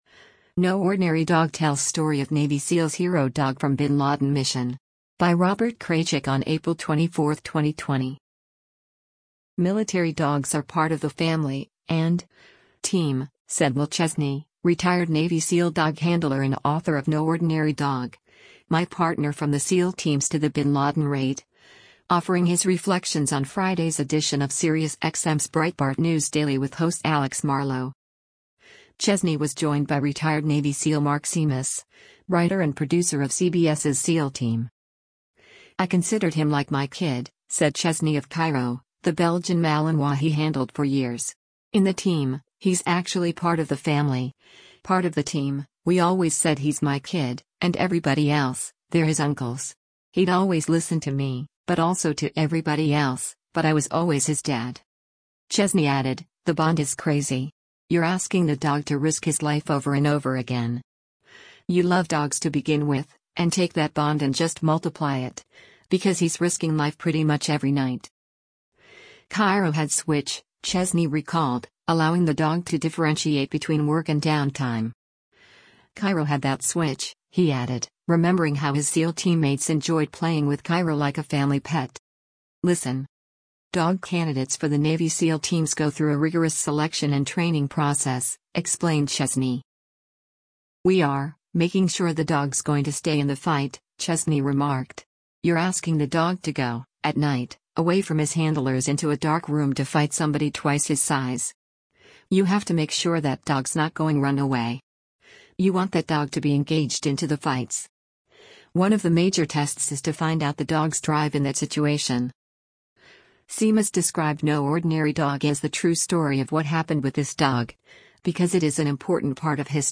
Breitbart News Daily broadcasts live on SiriusXM Patriot 125 weekdays from 6:00 a.m. to 9:00 a.m. Eastern.